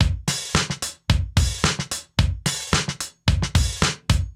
Index of /musicradar/sampled-funk-soul-samples/110bpm/Beats